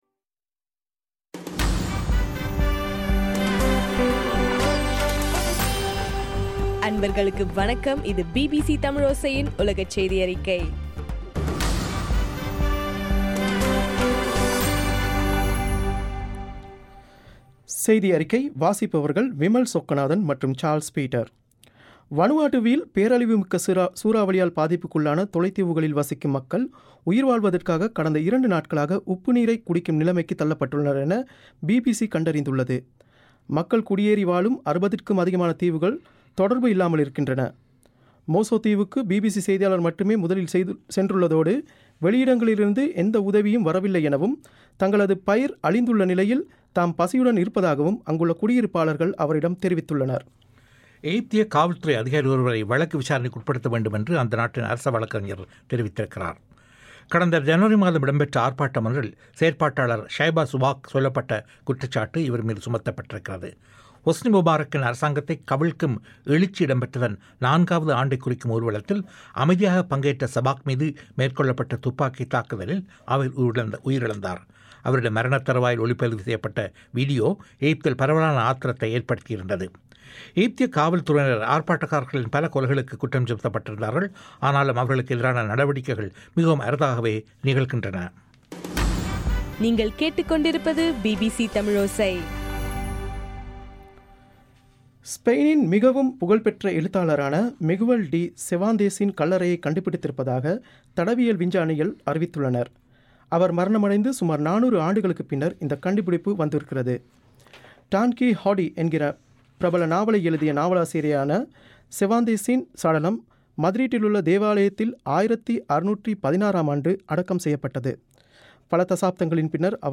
மார்ச் 17 2015 பிபிசி தமிழோசையின் உலகச் செய்திகள்